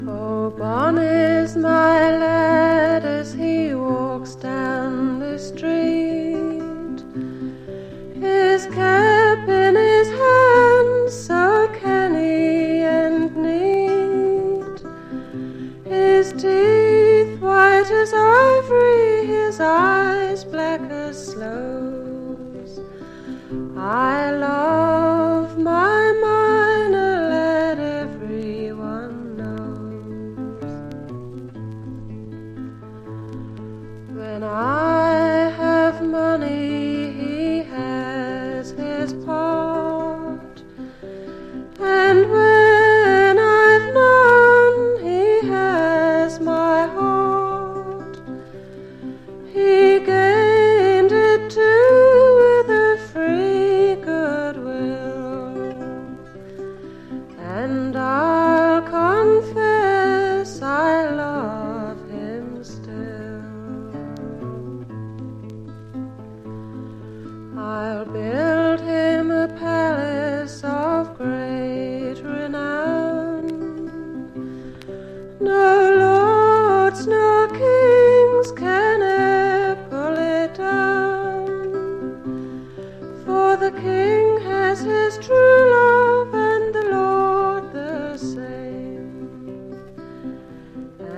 ブリティッシュ・フォーク・レジェンド！